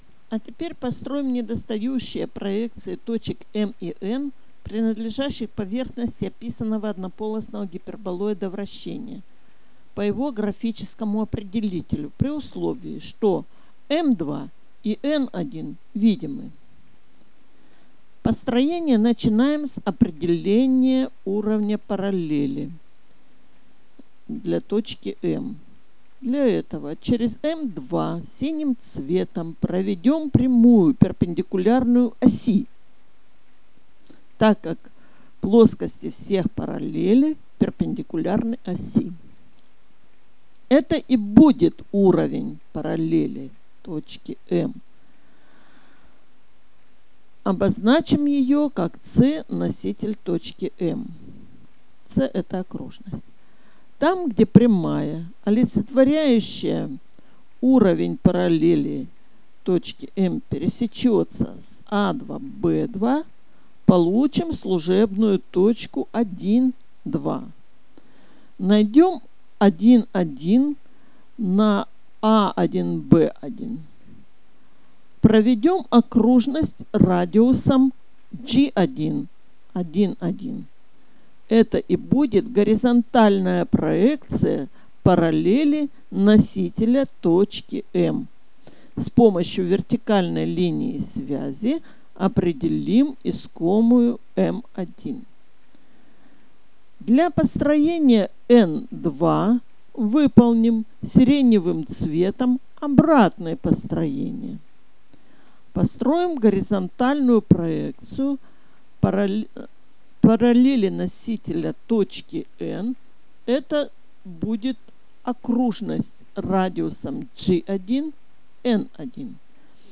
Аудио-комментарии